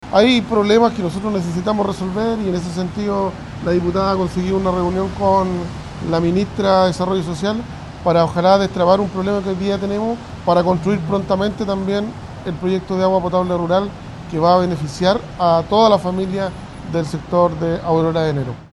El alcalde de Mulchén, Jorge Rivas, también se sumpo a este anhelo y señaló que esperan que esta reunión sirva para destrabar esta problemática.